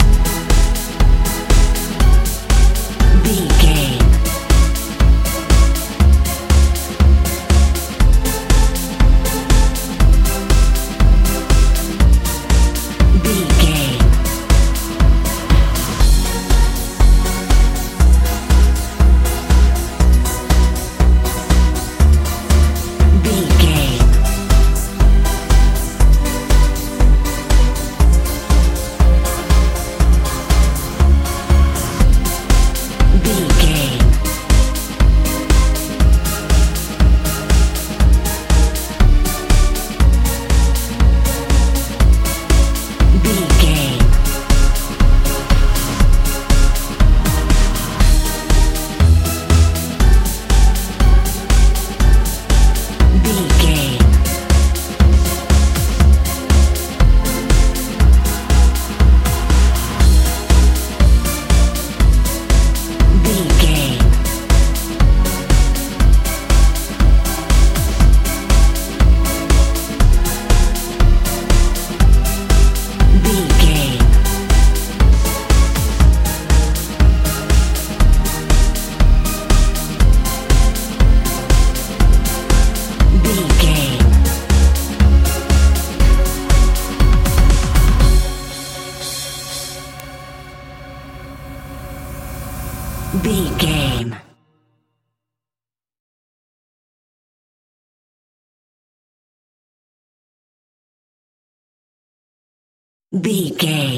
euro dance house feel
Ionian/Major
magical
mystical
synthesiser
bass guitar
drums
80s
90s
positive
sweet